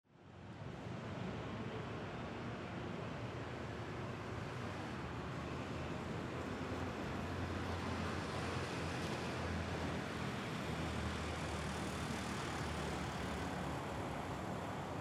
Background Sound Effects, Transportation Sound Effects
traffic_02-1-sample.mp3